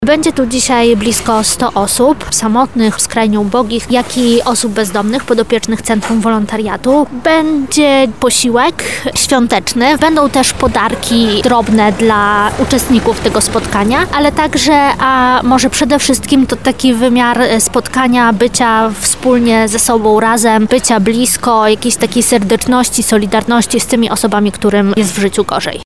Spotkanie miało na celu podzielenie się wspólnym posiłkiem wigilijnym oraz życzeniami z osobami samotnymi i znajdującymi się w trudnej sytuacji życiowej. Więcej na ten temat mówi  jedna z wolontariuszek SCW